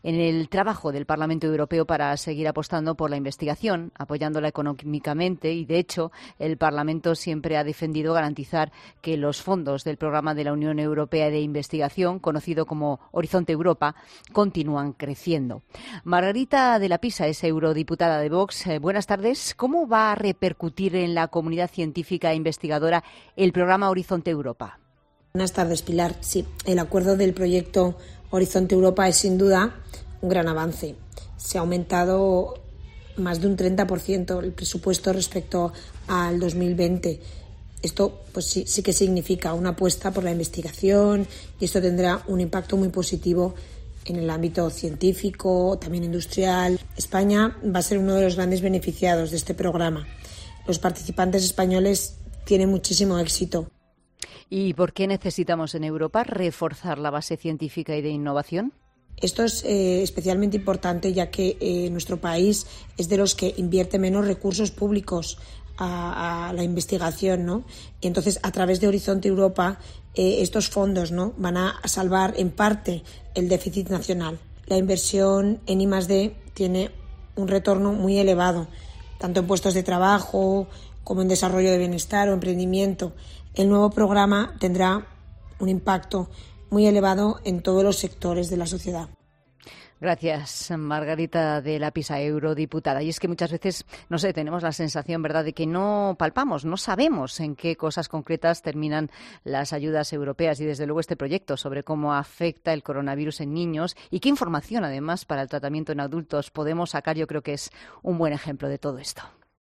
Margarita de la Pisa es eurodiputada de Vox y ha indicado en 'La Tarde' que se trata de un acuerdo que supone "un gran avance", ya que se ha aumentado más de un 30% el presupuesto con respecto al año 2020 en el que se apuesta firmemente por la investigación, con los que España se ha visto muy beneficiada.